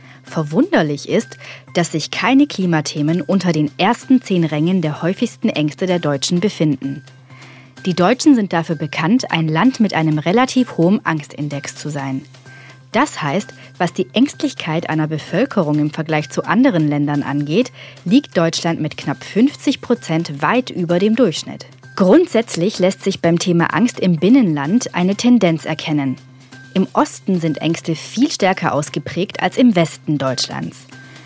Radio Micro-Europa, der Tübinger Campusfunk: Sendung „Angst“ am Sonntag, den 24. Oktober 2021 von 12 bis 13 Uhr im Freien Radio Wüste Welle 96,6 – Kabel: 97,45 Mhz, auch als Live-Stream im Internet.
Musik